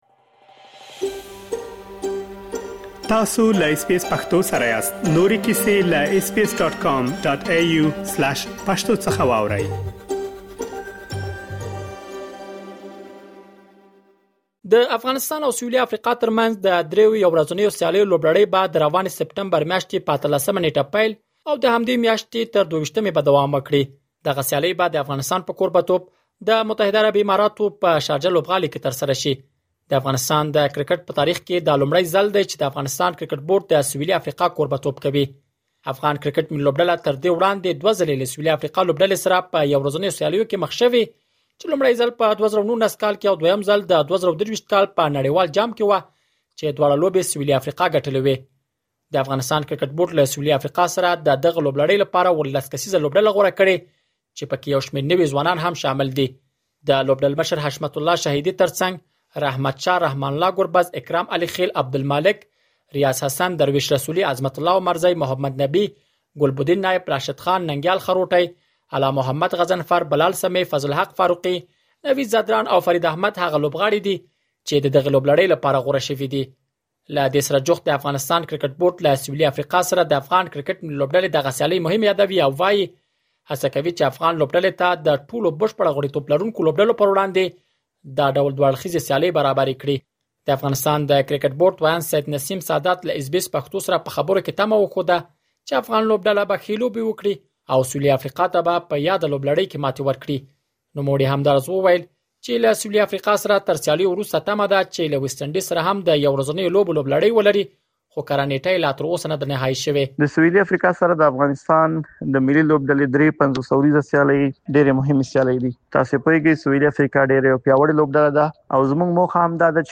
مهرباني وکړئ لا ډېر معلومات په رپوټ کې واورئ.